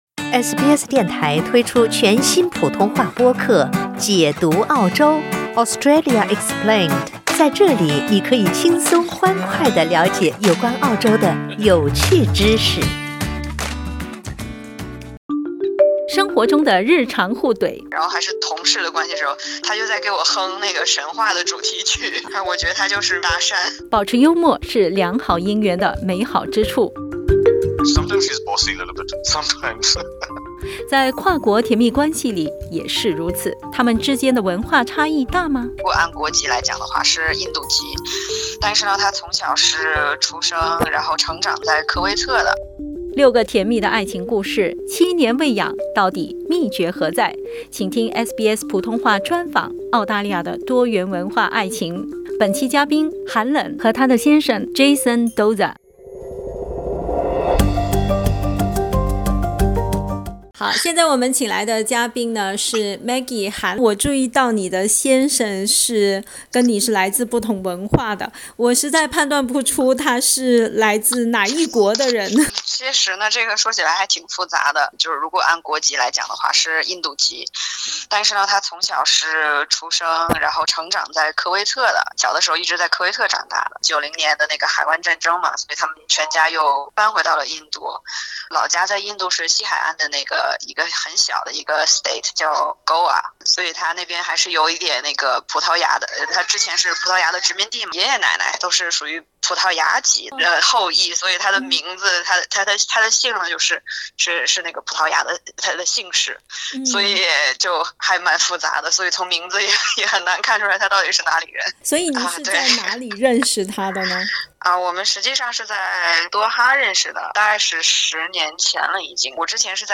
六个甜蜜的爱情故事，七年未痒，到底秘诀何在？SBS普通话专访 《澳大利亚的多元文化爱情》为你解密。